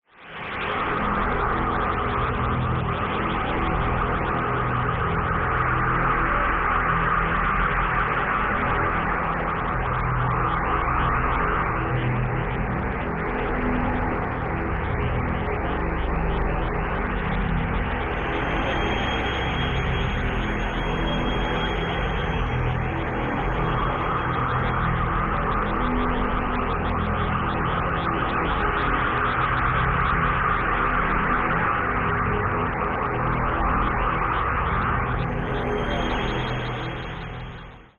サンプル音源は全てステレオ(2ch)です。